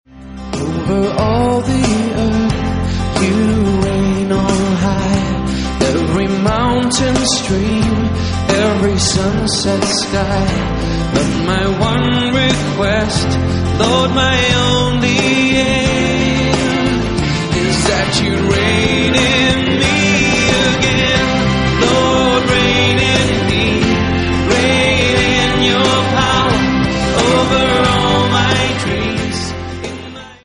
erstklassigen Live-Aufnahme
• Sachgebiet: Praise & Worship